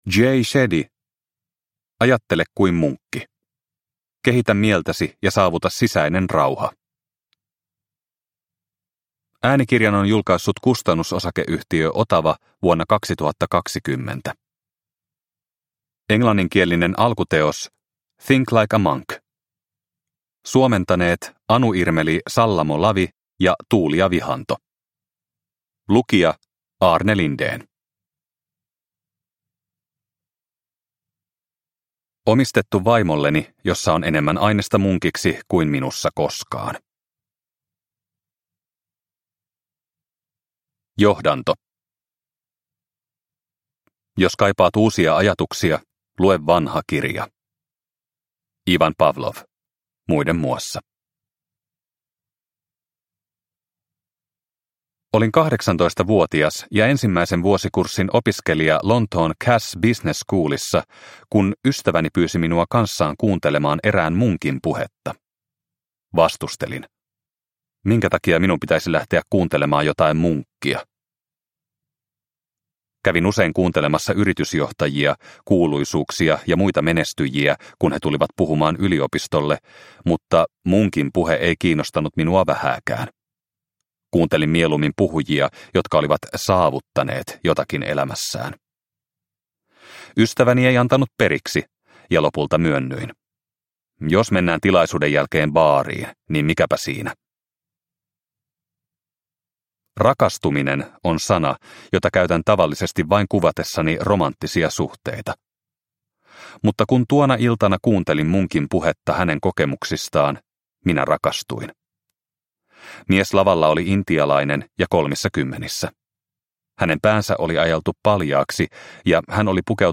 Ajattele kuin munkki – Ljudbok – Laddas ner